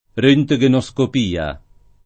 rHntgenoSkop&a o rHjgenoSkop&a; meno bene, alla ted.,